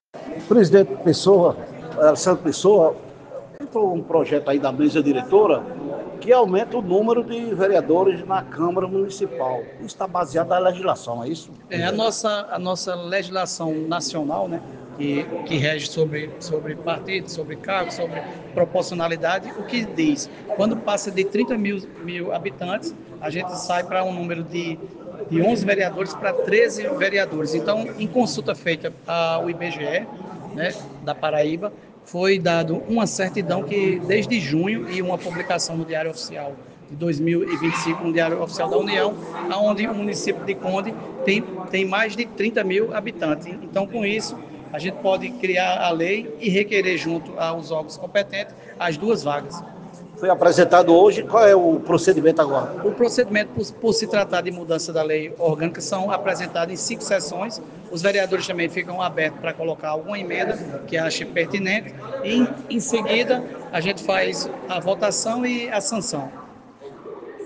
A ENTREVISTA